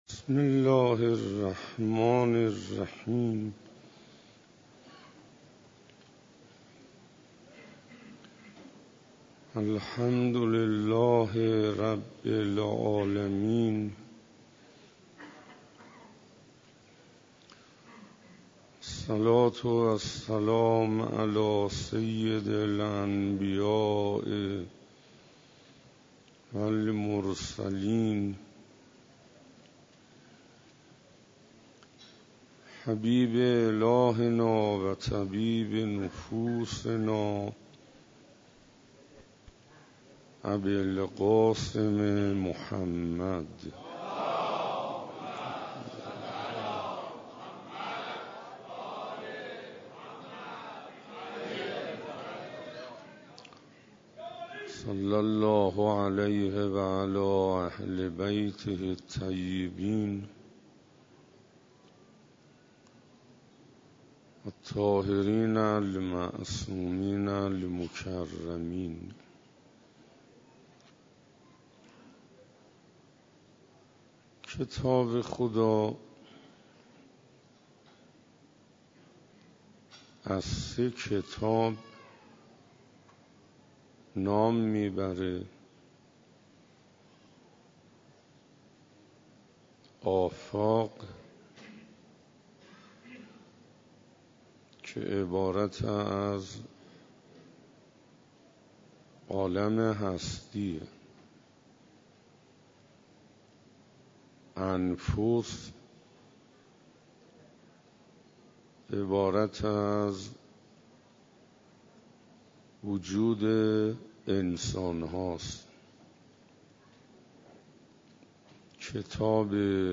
روز15 رمضان97 - مسجد امیر علیه السلام - رمضان